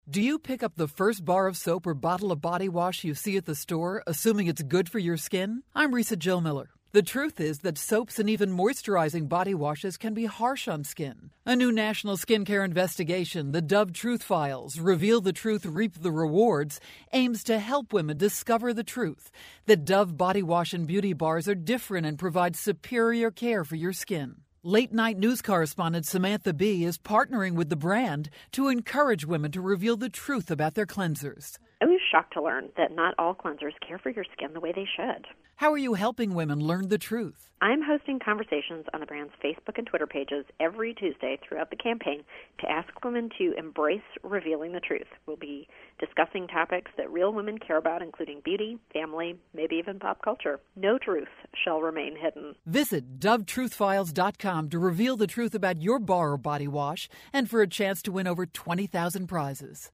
March 7, 2013Posted in: Audio News Release